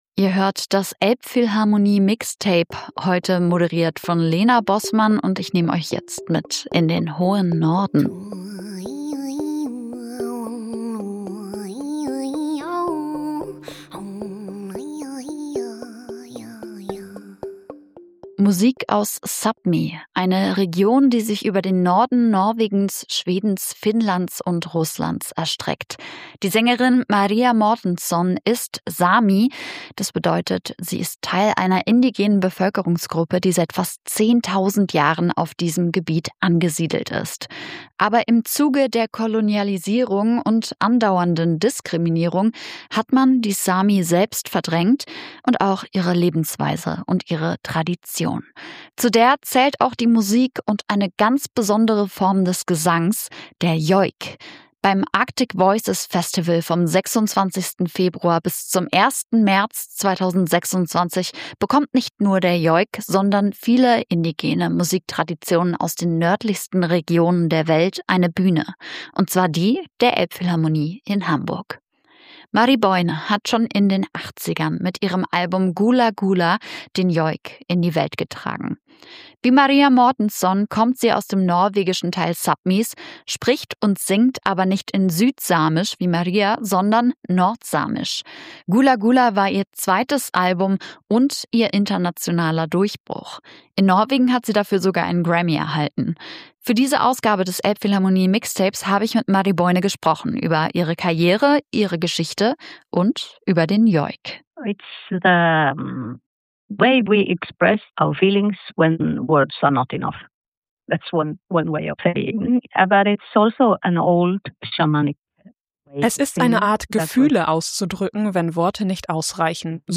zu Gast: Marie Boine